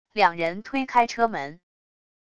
两人推开车门wav音频